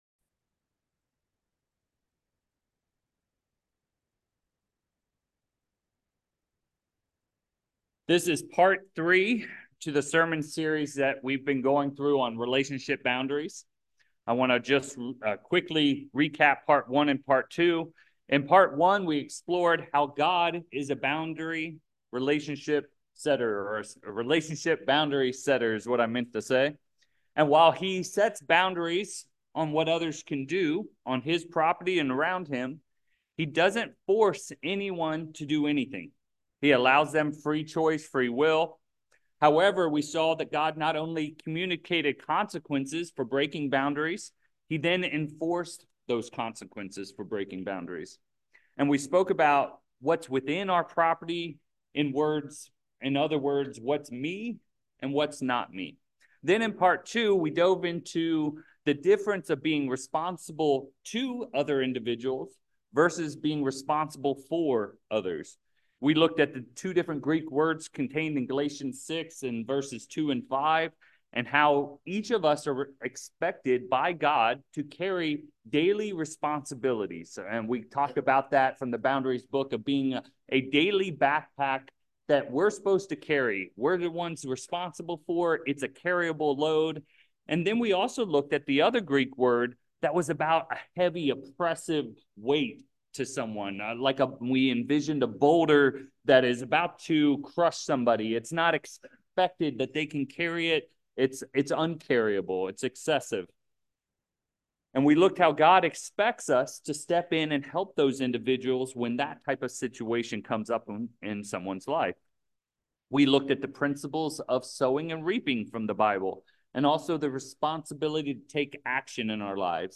Orinda In this third sermon of the four-part series, we build off the Biblical foundation we established in parts 1 and 2.